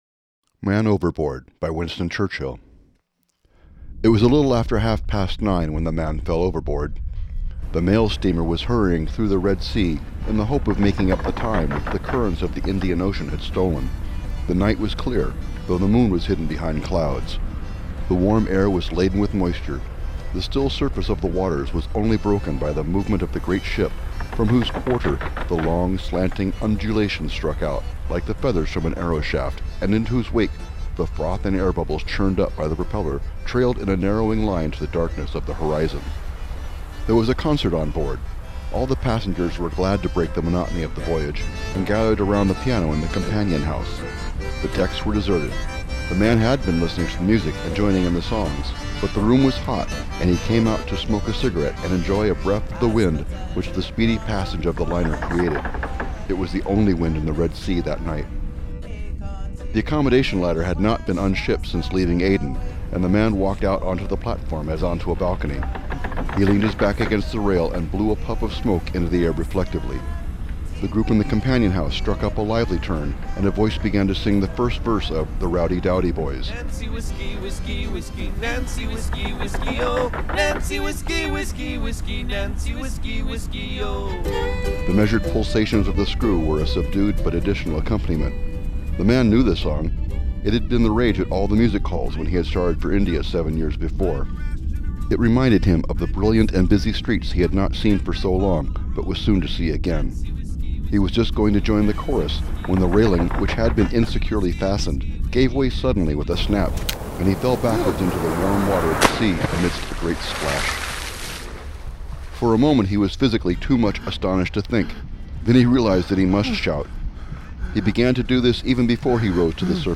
station ID